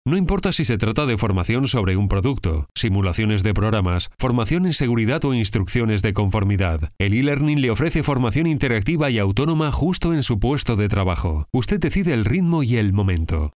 Voice:  Adult
Spanish Castilian voice talent.
Radio voiceover
Spanish  voice over actor.